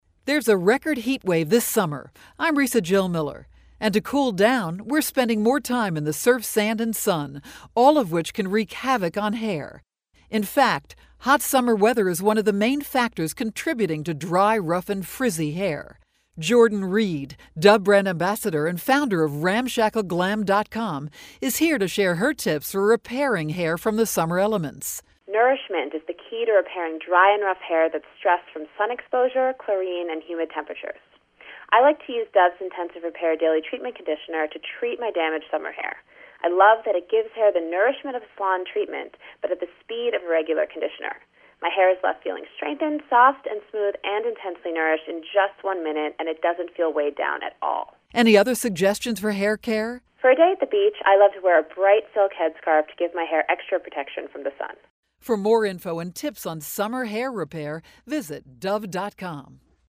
August 23, 2012Posted in: Audio News Release